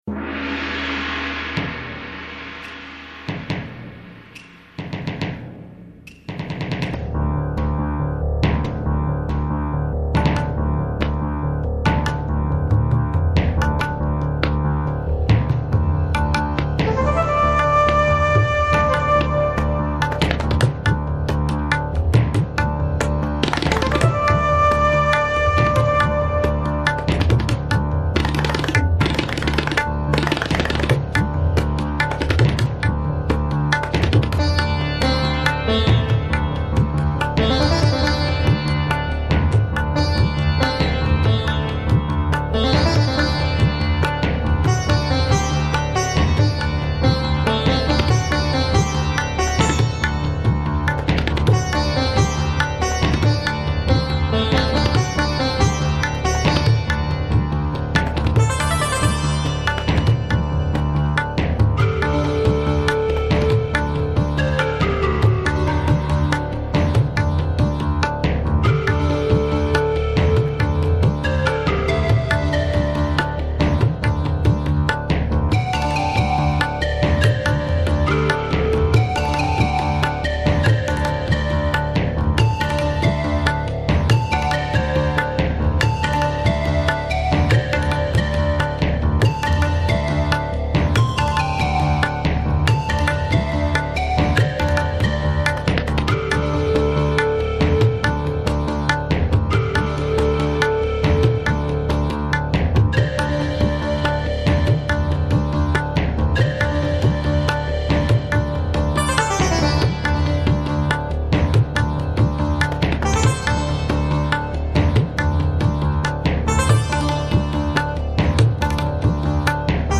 relax spiritual prayer